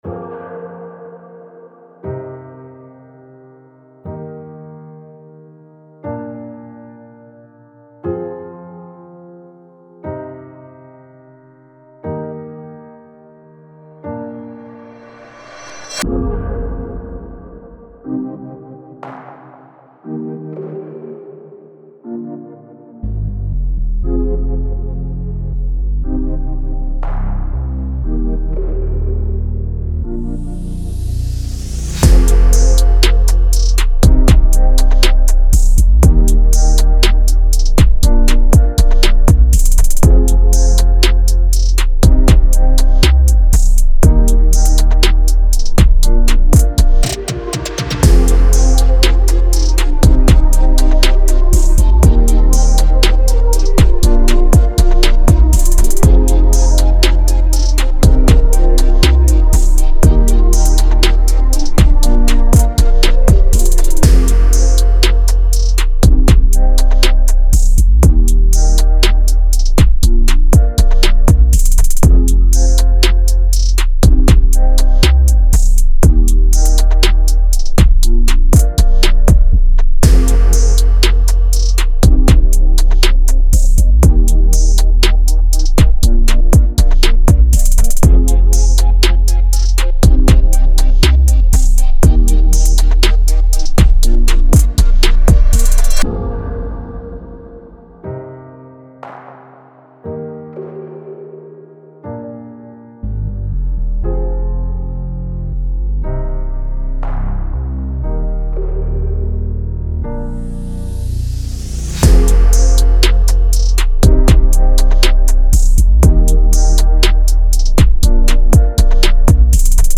Pop, R&B
C Min